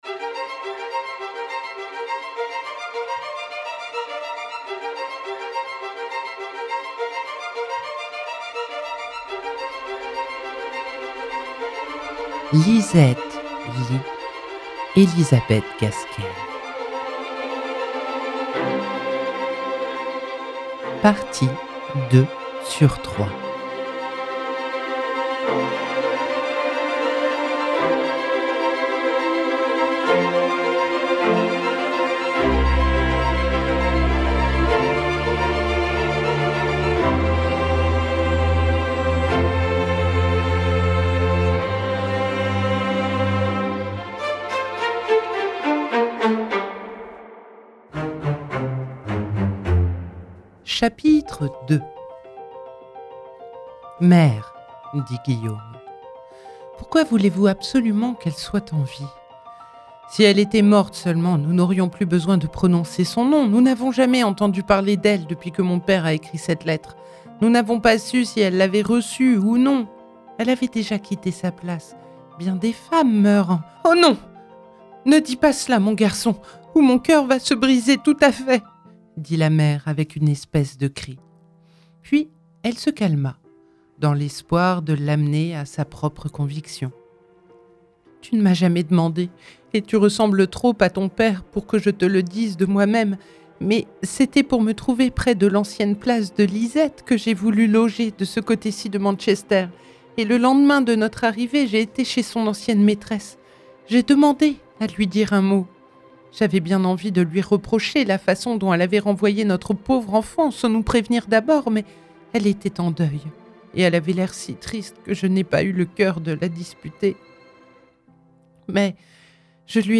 🎧 Lisette Leigh – Elizabeth Gaskell - Radiobook
Nouvelle, partie 2/3 (25:39)